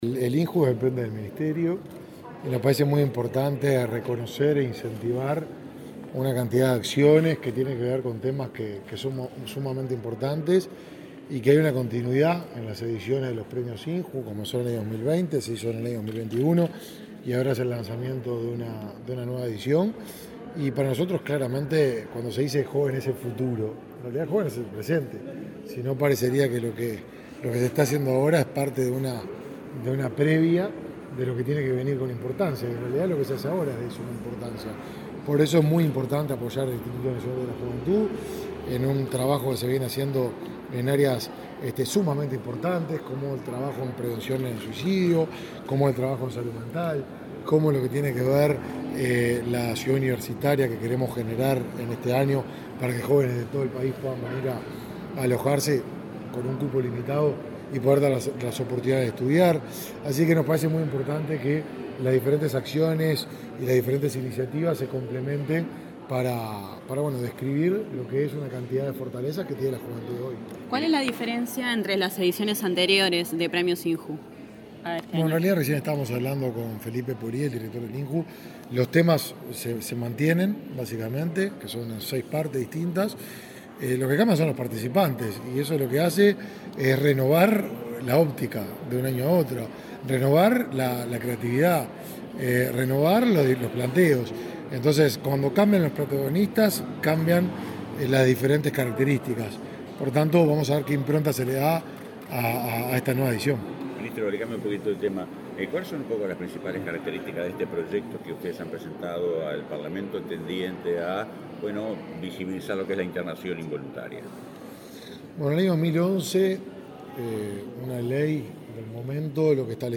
Declaraciones a la prensa del ministro de Desarrollo Social, Martín Lema
Este viernes 17, el ministro de Desarrollo Social, Martín Lema, participó en el lanzamiento de la tercera edición de los Premios INJU.